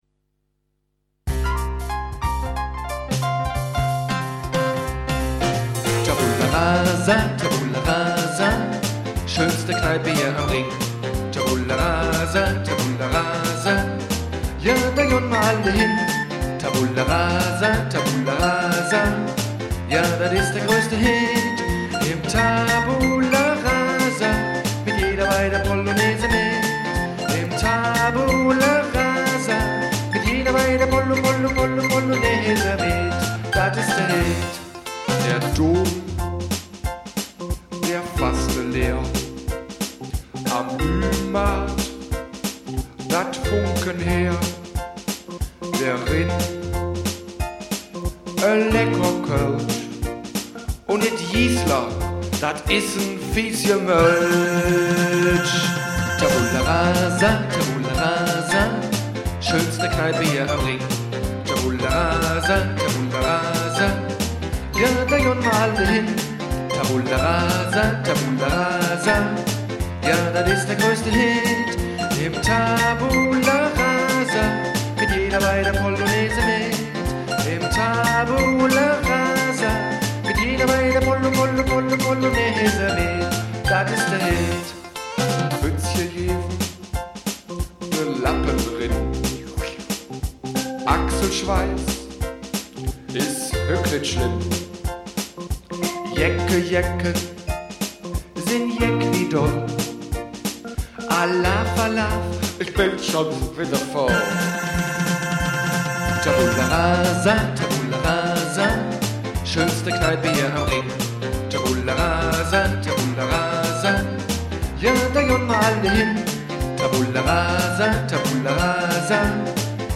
Welche Kneipe hat schon seine eigene Kneipen-Karnevals-Hymne. Und alle singen mit…